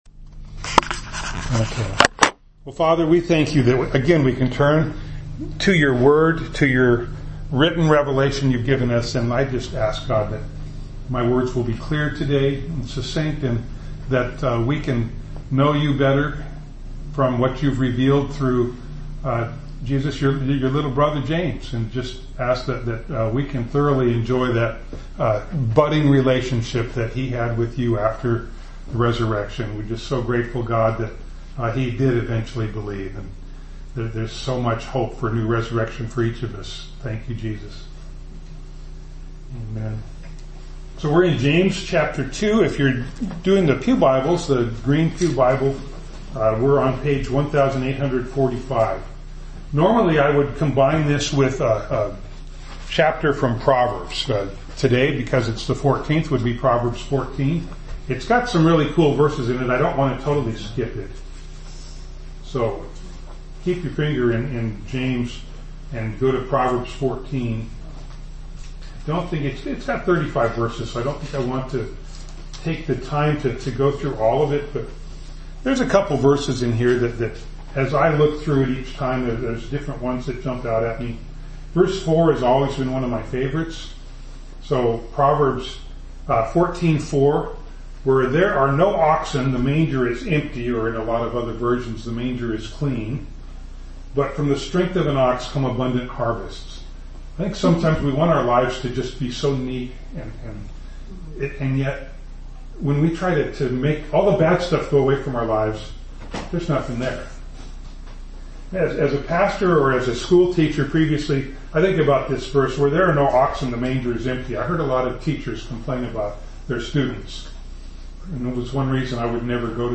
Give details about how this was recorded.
James 2:18 Service Type: Sunday Morning Bible Text